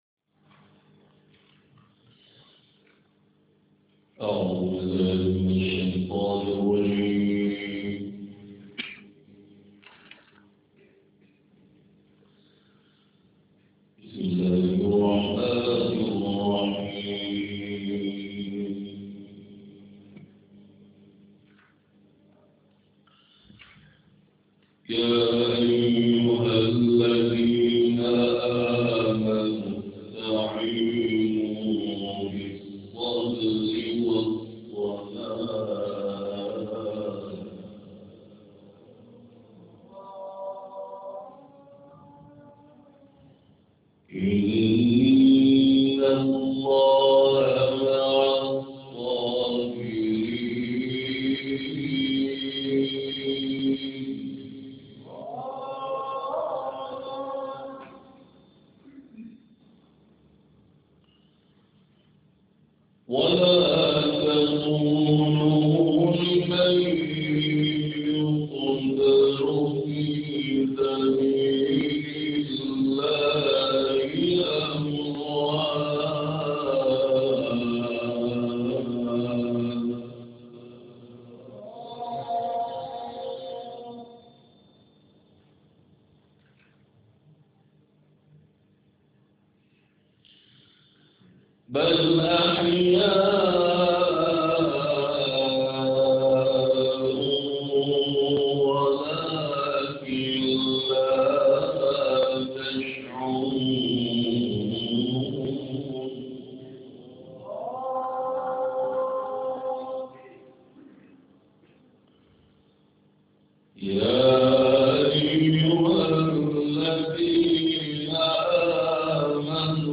تلاوت
این تلاوت روز شنبه، 19 فروردین‌ماه در مسجد حضرت رقیه(س) شهرک امام حسین(ع) اسلامشهر اجرا شده است و تصویر بالا مربوط به این جلسه است.